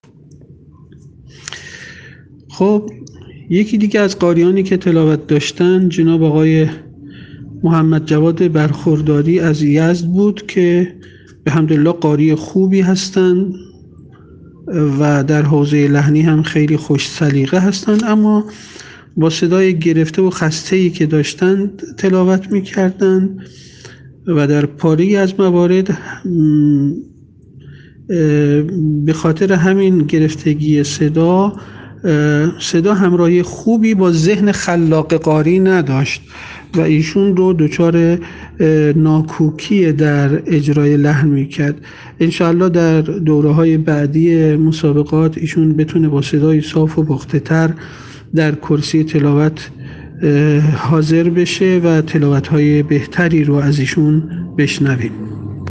شش قاری، شب گذشته، در روز دوم مسابقات سراسری قرآن به تلاوت آیاتی از کلام‌الله مجید پرداختند، ‌آشنا نبودن قاریان با آیات، ‌ضعف و خستگی صدا و اشتباه در اجرای آکسان‌ها از جمله اشکالات قاریان در این روز بود.
با صدای گرفته و خسته‌ای تلاوت کرد و در پاره‌ای از موارد به خاطر همین گرفتگی، صدا همراهی خوبی با ذهن خلاق قاری نداشت و ایشان را دچار ناکوکی در اجرای لحن کرد.